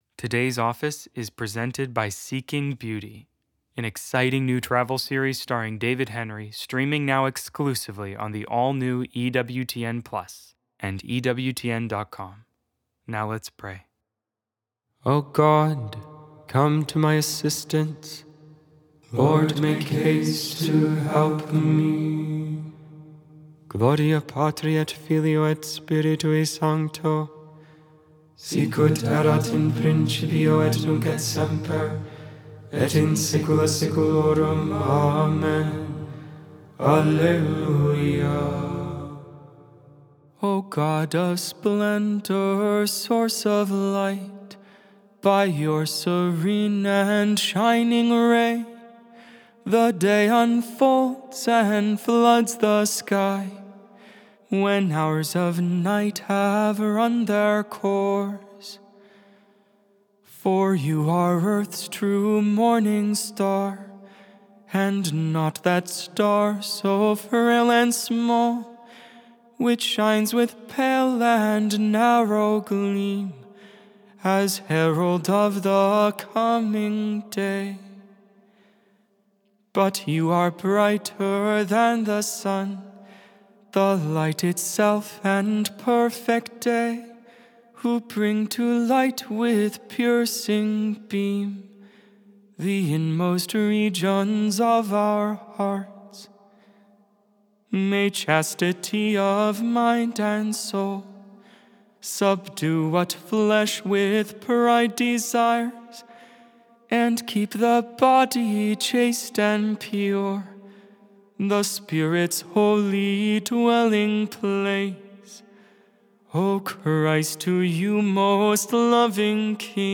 Lauds, Morning Prayer for the 2nd Monday in Ordinary Time, January 20, 2025.Made without AI. 100% human vocals, 100% real prayer.